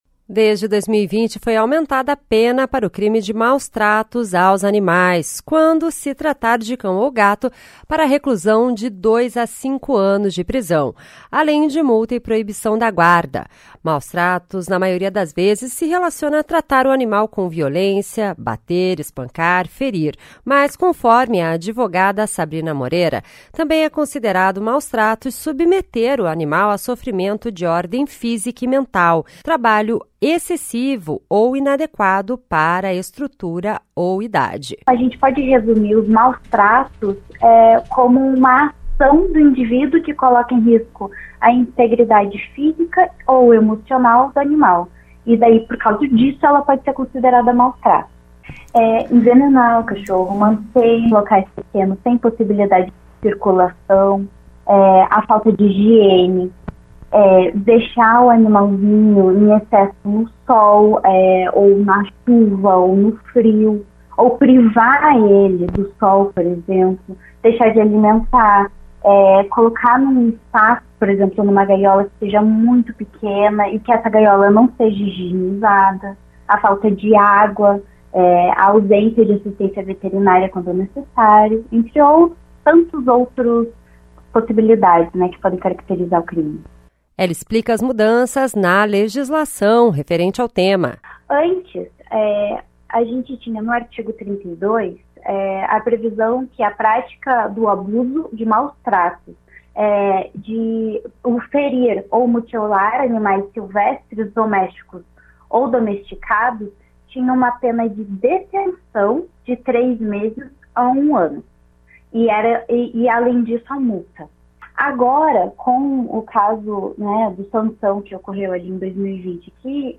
Ela explica as mudanças na legislação referente ao tema.
A advogada fala que é preciso uma mudança social em relação à proteção dos animais para acabar com os maus-tratos.